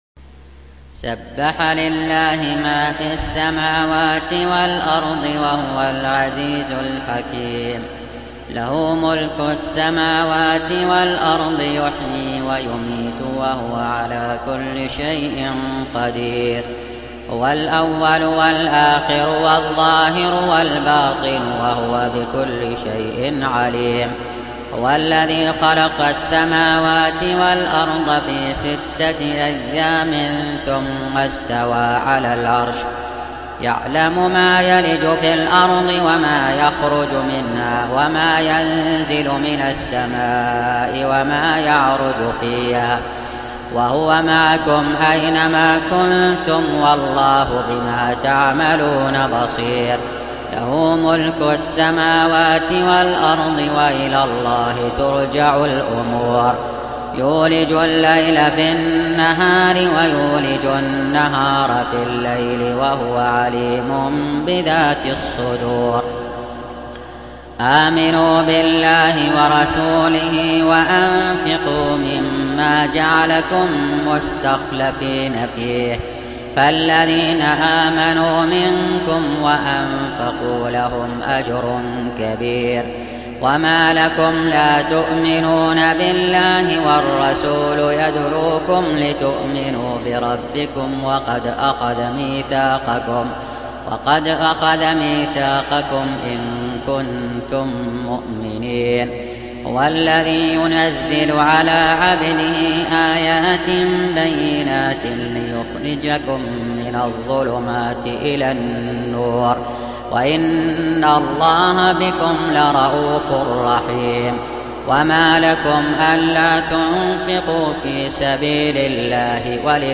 57. Surah Al-Had�d سورة الحديد Audio Quran Tarteel Recitation
Surah Repeating تكرار السورة Download Surah حمّل السورة Reciting Murattalah Audio for 57. Surah Al-Had�d سورة الحديد N.B *Surah Includes Al-Basmalah Reciters Sequents تتابع التلاوات Reciters Repeats تكرار التلاوات